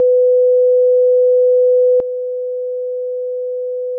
[wav]       50Hzの音データ/基準音と低減音 [自動車の低音マフラーなど] 80Hzの音データ/基準音と低減音 [低音のいびきや大型犬の鳴き声など] 100Hzの音データ/基準音と低減音 [会話音[男性の声]など] 125Hzの音データ/基準音と低減音 [自動車の低音マフラーなど] 250Hzの音データ/基準音と低減音 [低音のいびきや大型犬の鳴き声など]